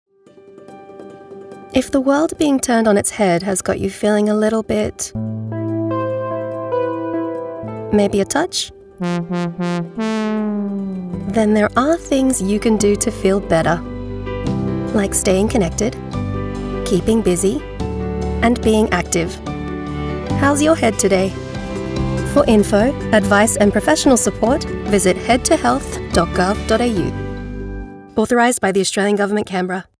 coronavirus-covid-19-radio-how-s-your-head-today.wav